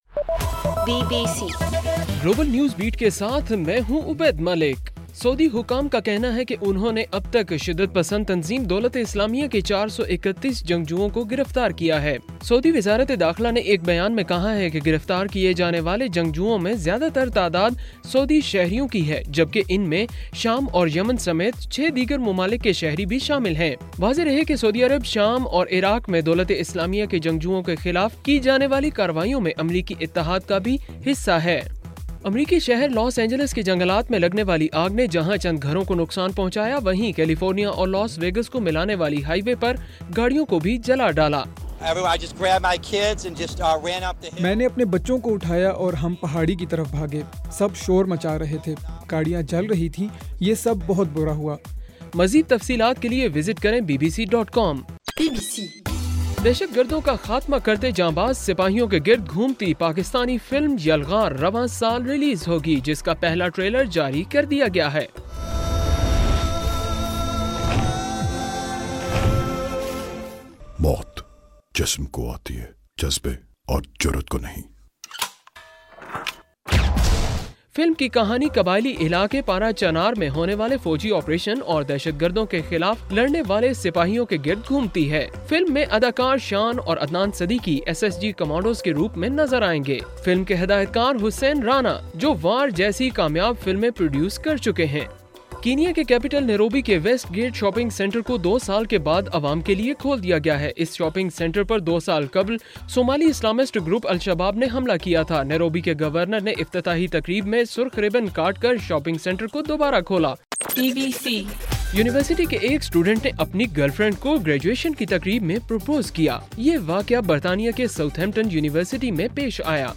جولائی 18: رات 11 بجے کا گلوبل نیوز بیٹ بُلیٹن